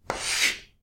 sounds_chalk_write_01.ogg